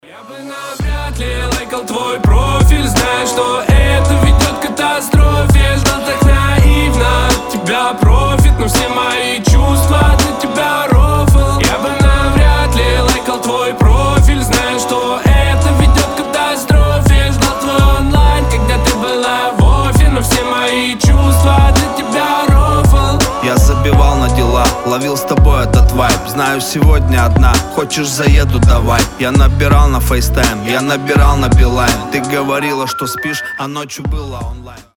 • Качество: 320, Stereo
мужской голос
лирика
грустные
качающие